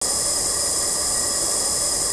m134_reload2_gas2.wav